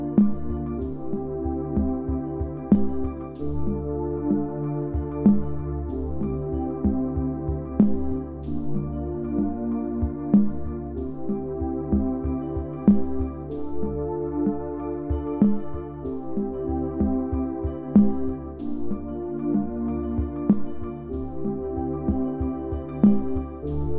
Bass Or Backing Vocals Pop (1980s) 4:53 Buy £1.50